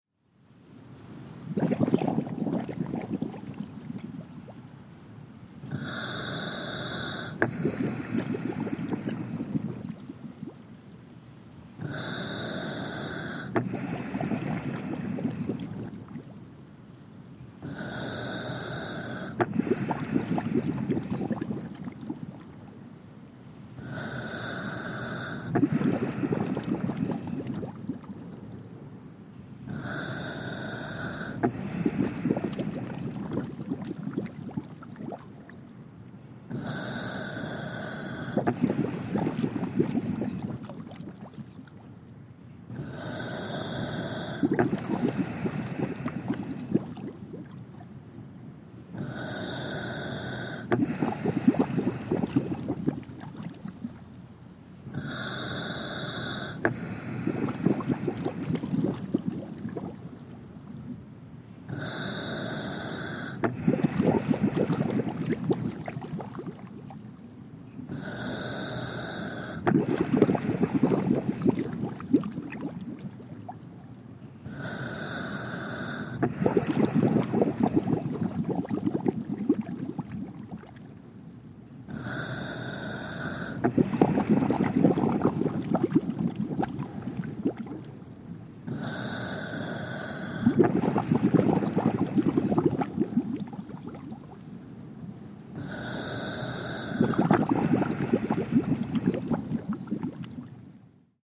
WATER-UNDERWATER FX SCUBA: Regulator breathing & bubbles under water, close up.